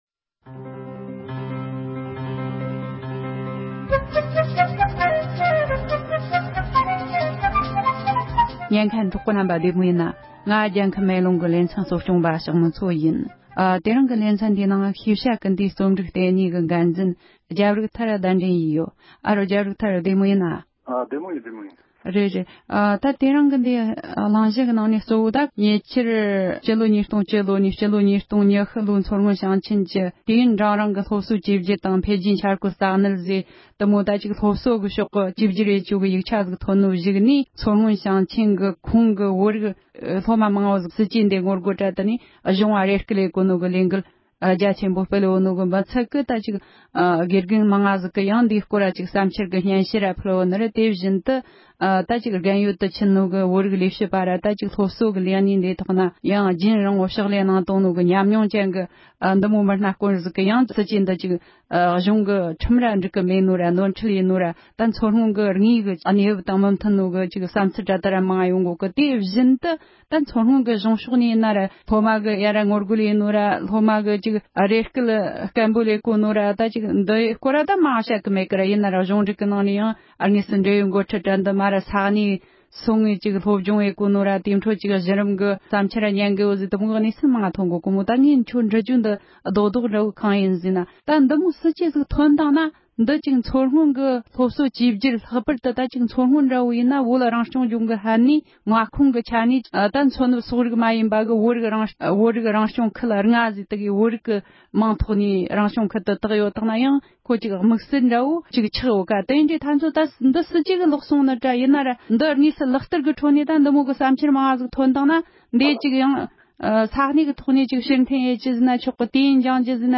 བོད་ནང་སློབ་ཚན་བཅོས་བསྒྱུར་ལ་ངོ་རྒོལ་བྱས་པའི་ཐད་གླེང་མོལ།